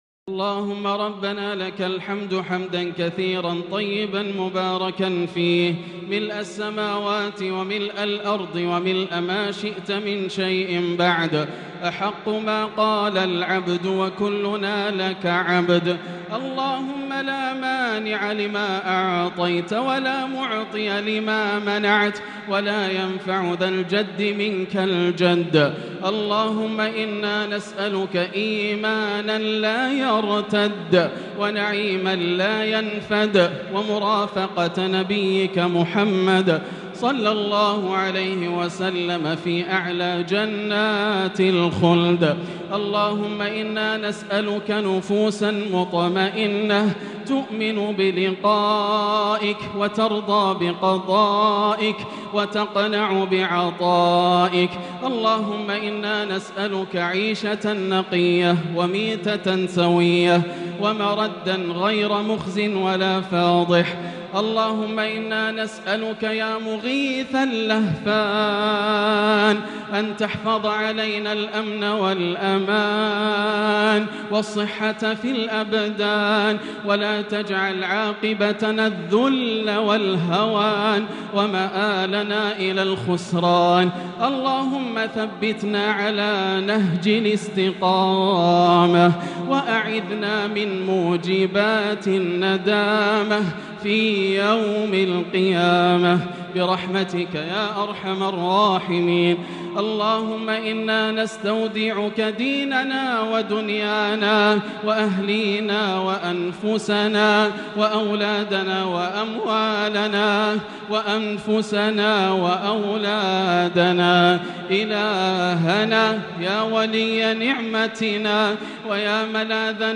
دعاء القنوت ليلة 8 رمضان 1443هـ | Dua for the night of 8 Ramadan 1443H > تراويح الحرم المكي عام 1443 🕋 > التراويح - تلاوات الحرمين